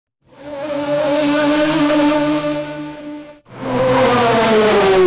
sound-effect-generation text-to-audio